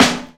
• Focused Acoustic Snare B Key 49.wav
Royality free acoustic snare tuned to the B note. Loudest frequency: 2095Hz
focused-acoustic-snare-b-key-49-2jG.wav